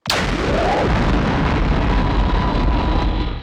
SoundEffects / Bullet / 阳电子束.wav
阳电子束.wav